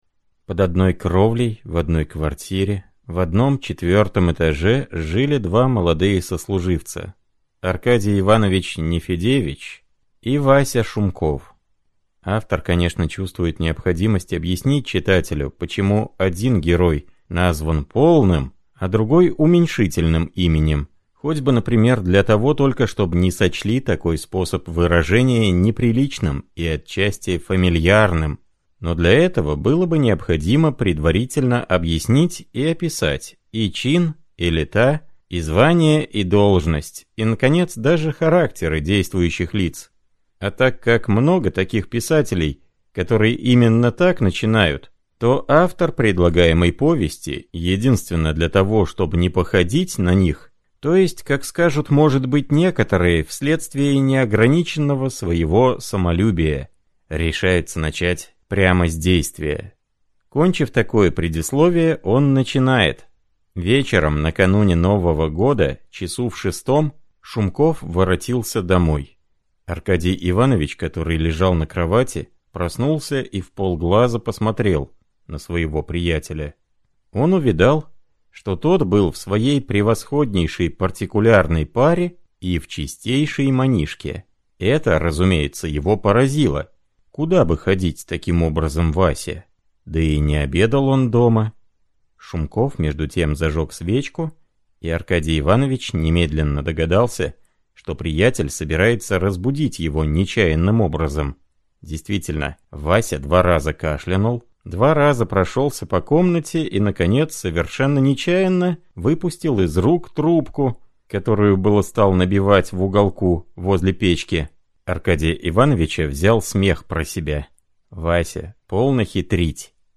Аудиокнига Слабое сердце | Библиотека аудиокниг